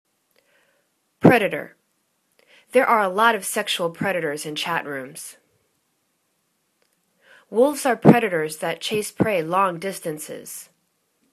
pre.da.tor     /'predətər/    n